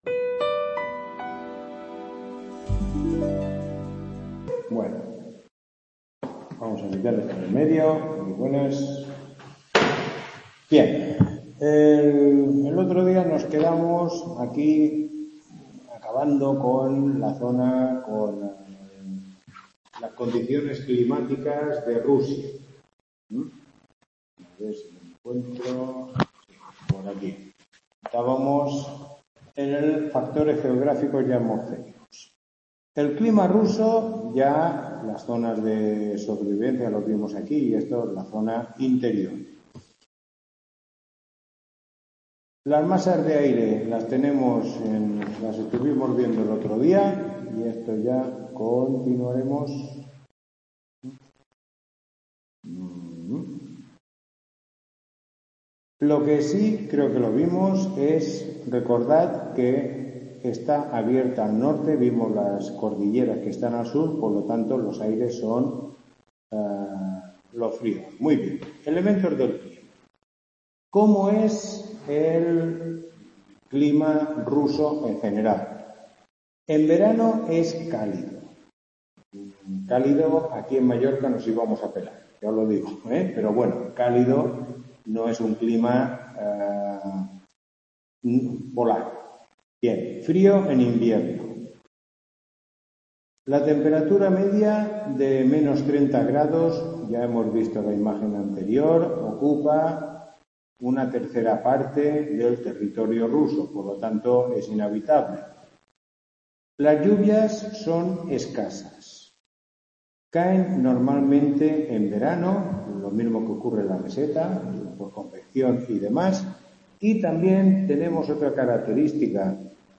Tutoría 04